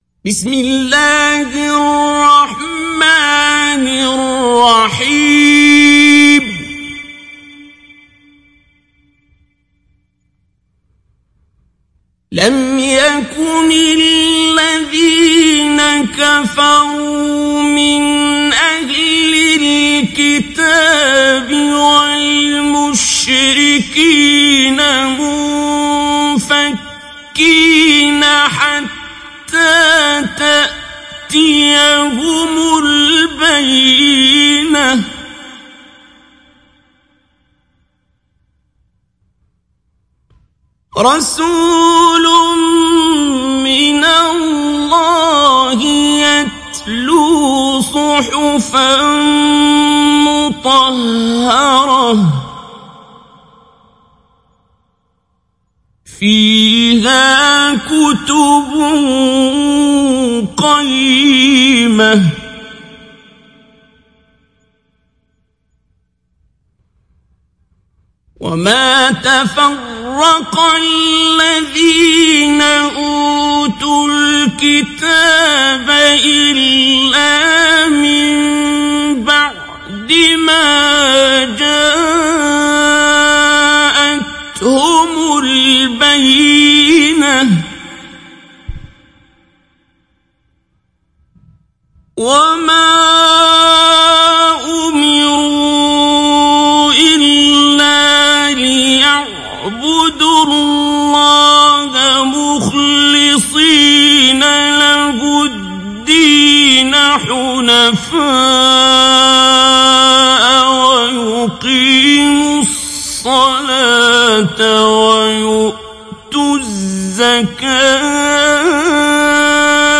ডাউনলোড সূরা আল-বায়্যিনাহ্ Abdul Basit Abd Alsamad Mujawwad